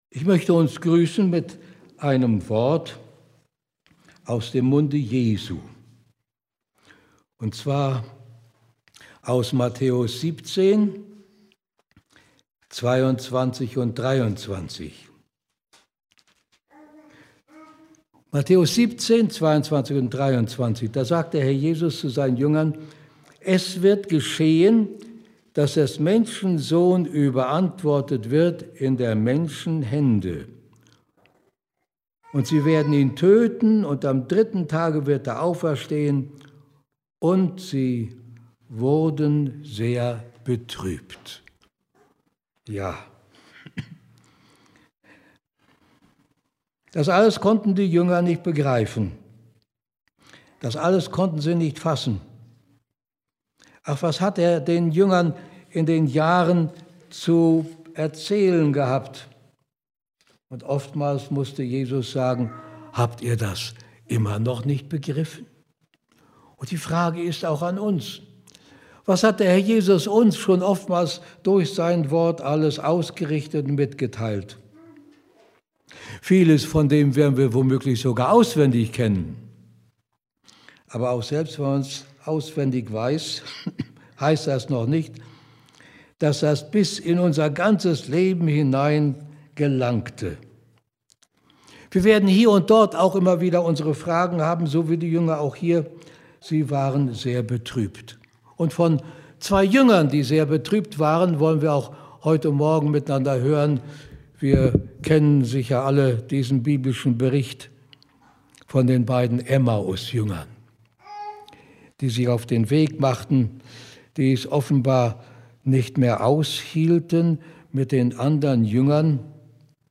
Ausgangspunkt der Predigt ist die Traurigkeit der Jünger angesichts von Kreuz und Grab.